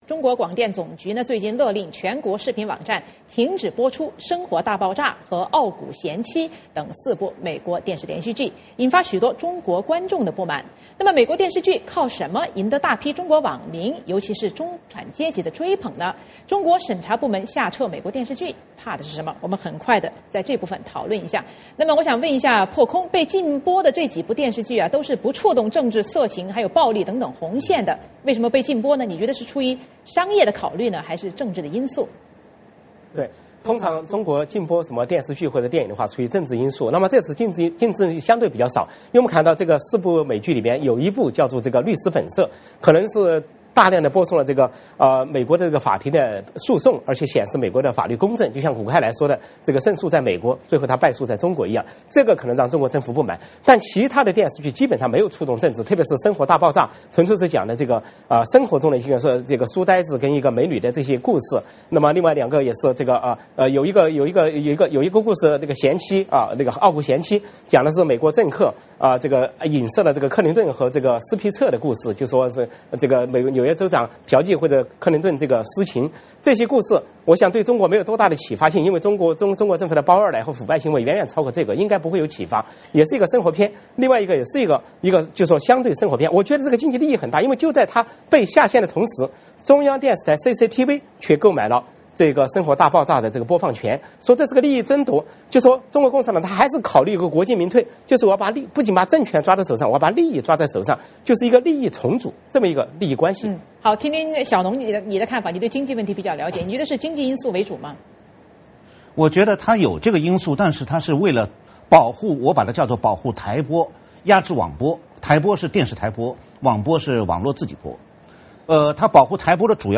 参加今天讨论的四位嘉宾是